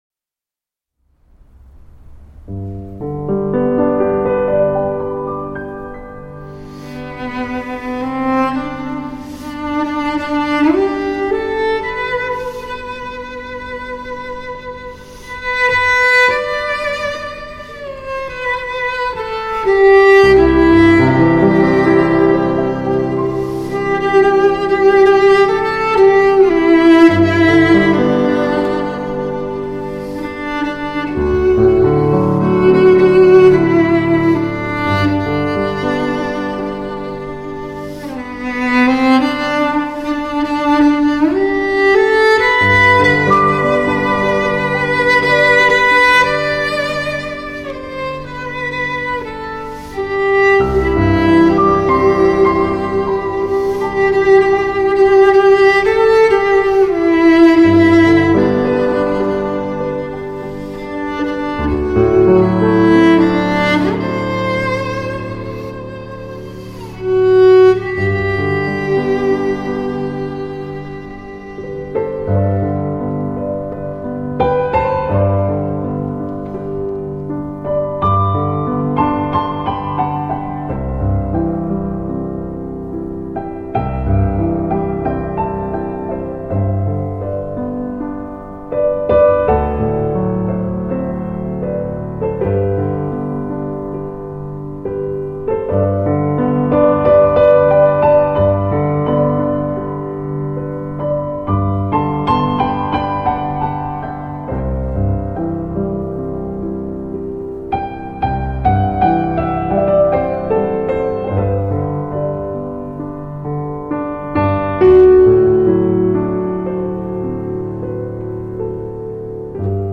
唱片类型：Church music
专辑格式：DTS-CD-5.1声道
高雅的大提琴和水亮剔透的钢琴交织出的海上琴音，洗涤您的心灵吧 !